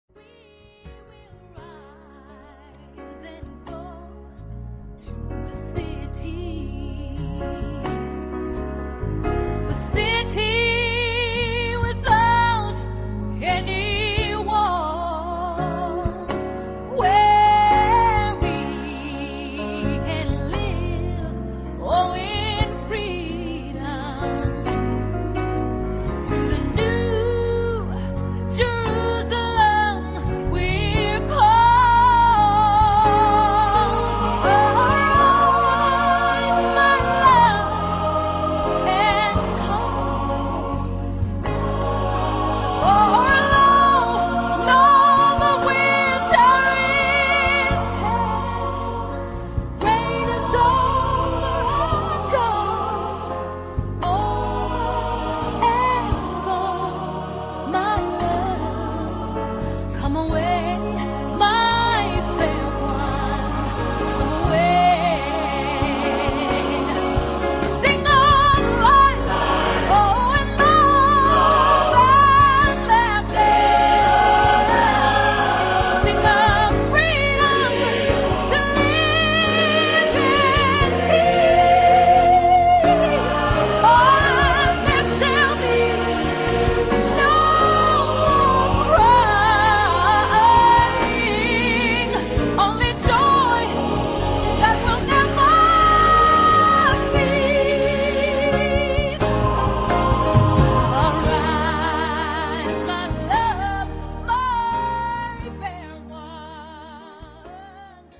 Genre-Style-Forme : Gospel ; Sacré
Caractère de la pièce : inspiré
Type de choeur : SSATTB  (6 voix mixtes )
Solistes : soprano (1)  (1 soliste(s))
Instruments : Piano (1) ; Basse (1) ; Batterie (1)
Tonalité : ré bémol majeur